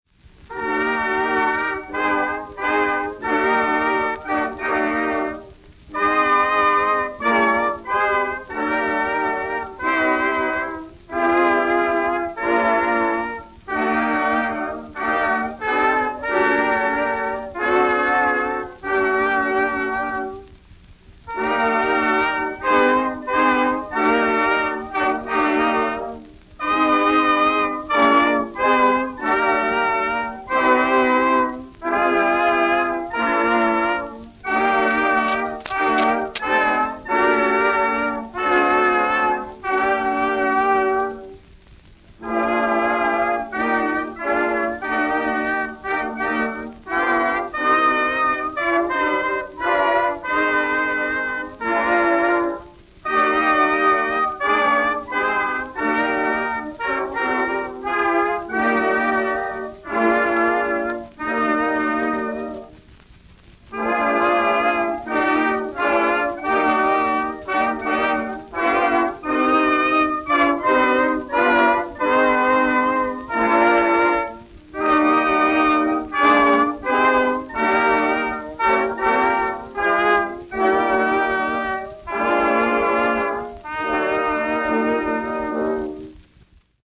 Послушайте одну лишь мелодию гимна «Боже, царя храни», возвышенную и величественную, по духу своему более соотвествующую гимну «Коль славен», чем «Грому победы». Запись выполнена в 1914 году (Edison Blue Amberol Record 2483). Исполняет Нью-Йоркский военный оркестр: